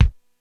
Index of /90_sSampleCDs/300 Drum Machines/Korg DSS-1/Drums01/03
Kick 02.wav